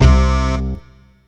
GUnit Synth12.wav